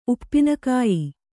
♪ uppina kāyi